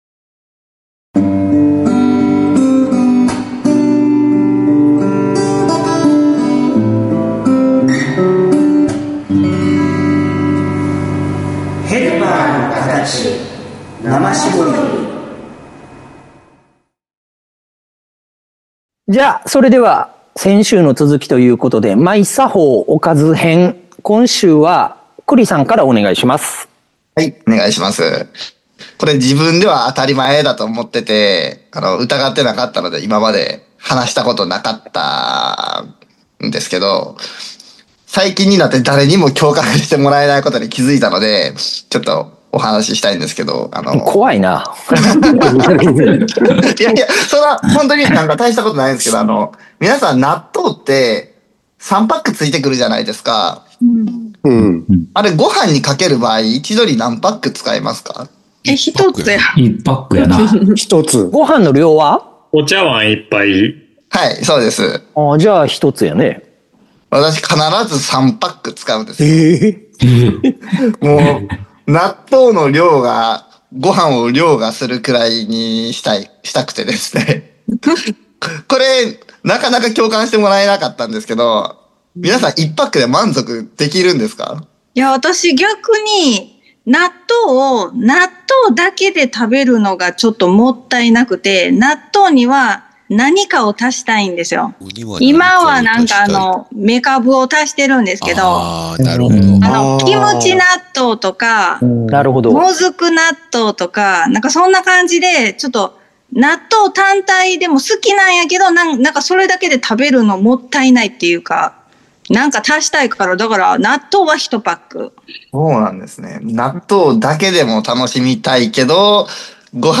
ヘルパーのカタチ 生搾り Podcast – 三重県の片隅、とある居酒屋。現場スタッフを中心に集まったメンバーが語る臨場感たっぷりのリアル現代福祉論。